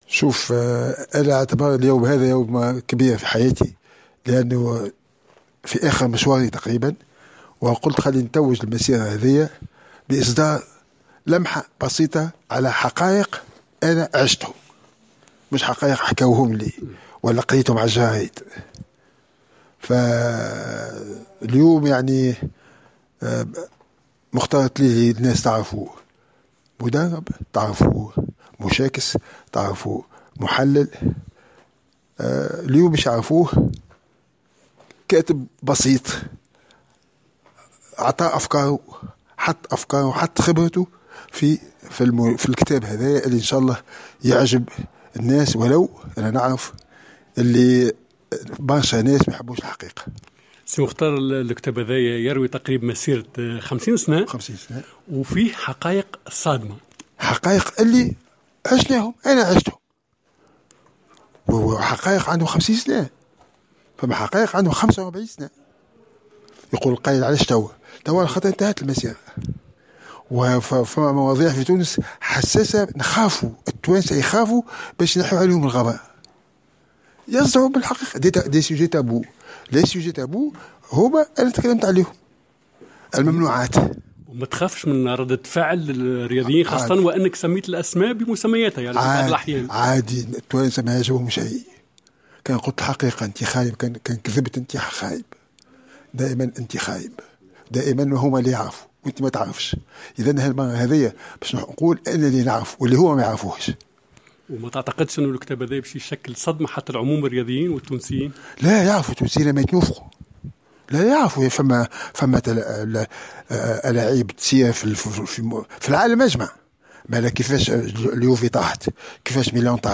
عقد المدرب مختار التليلي صباح اليوم السبت 20 مارس 2021 ندوة صحفية بمناسبة حفل اصدار كتابه الجديد "Mon Combat ".